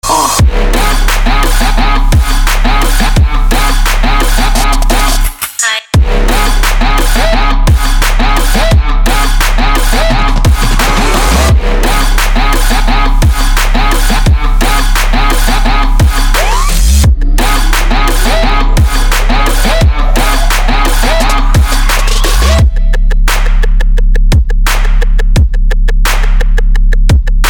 • Качество: 320, Stereo
Trap